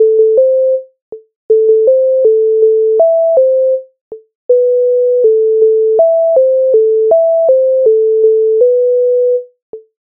MIDI файл завантажено в тональності a-moll
Воротарчику Українська народна пісня з Повного зібрання творів М.Лисенка, т.19